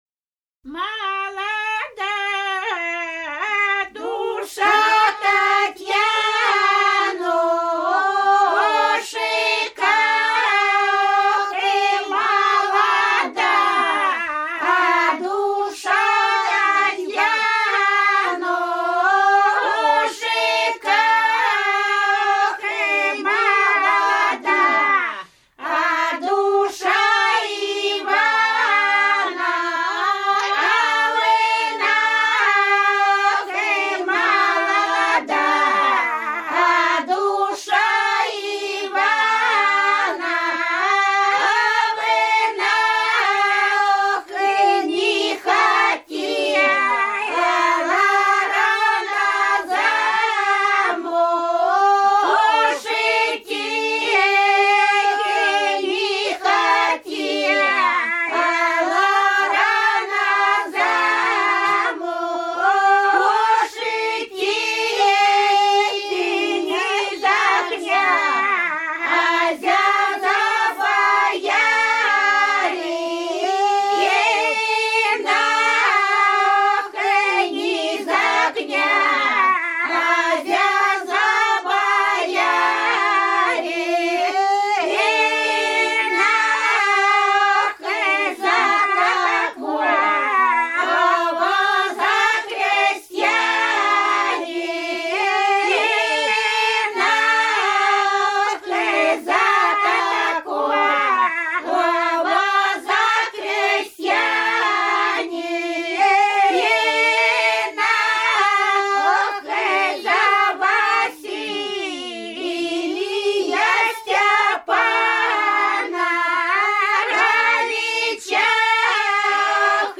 Рязань Кутуково «Молода душа», свадебная.